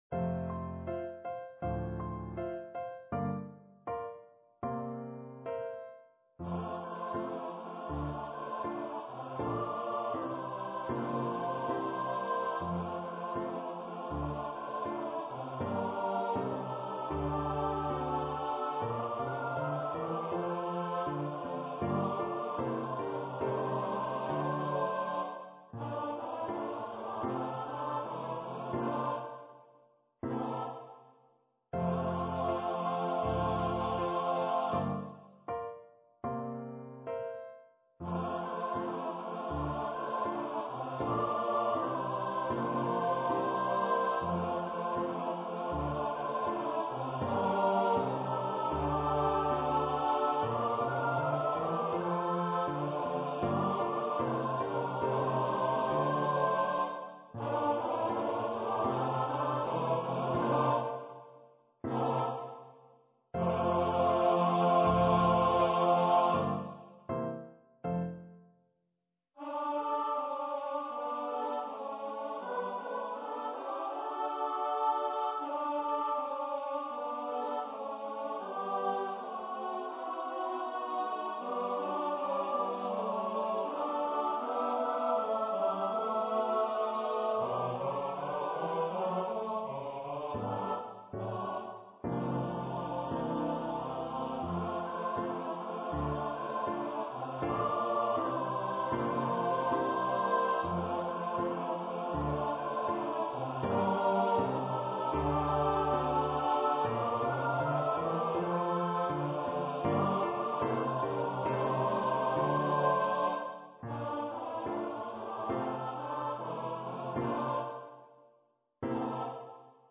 for SATB choir
carol